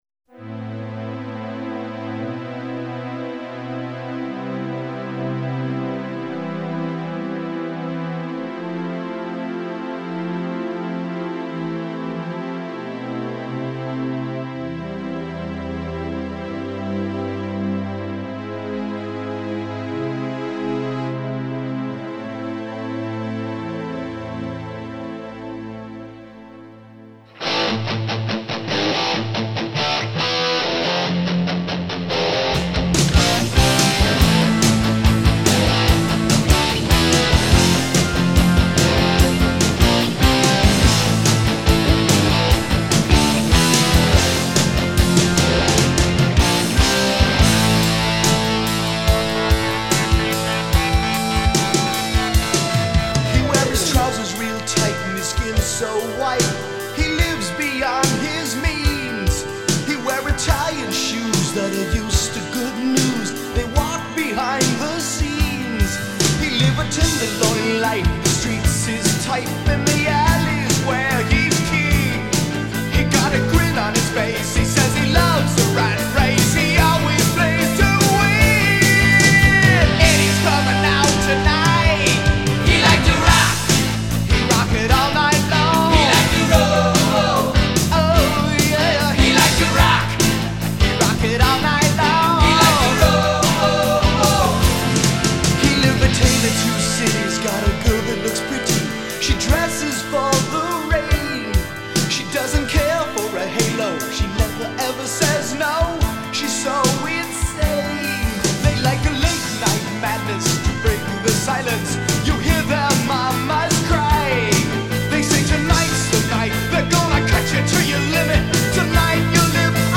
かっこいいロックチューンで元気も出ます。